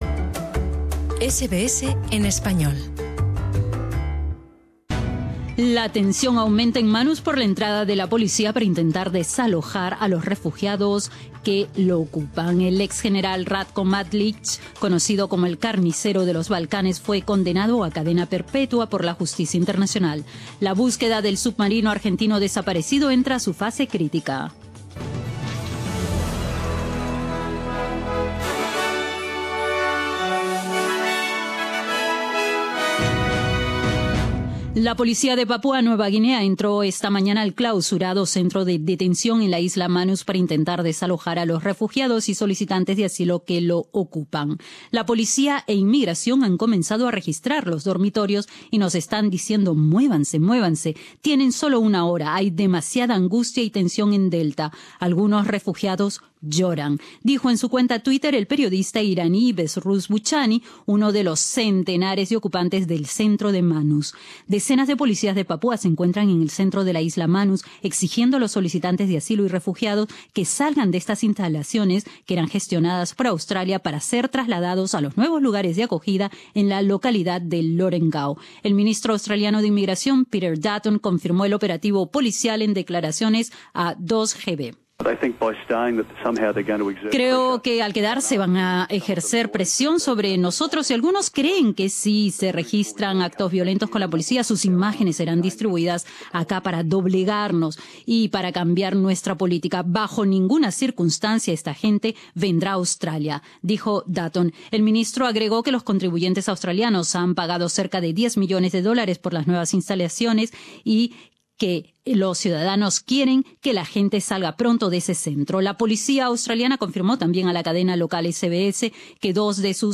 Extracto del boletín de noticias de SBS